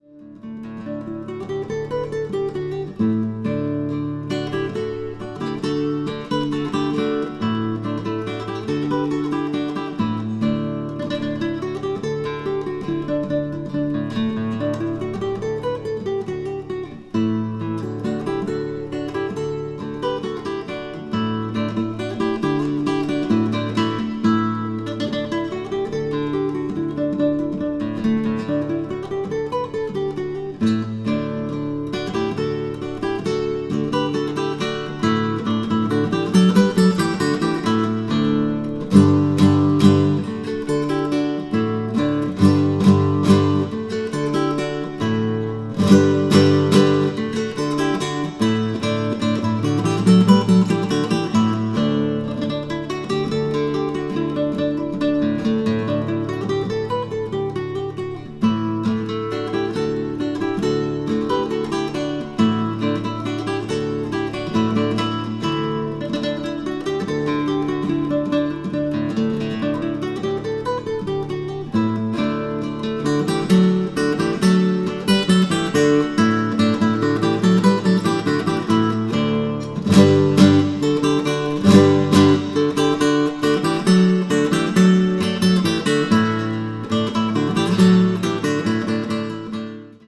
acoustic   country   folk   guitar solo   new age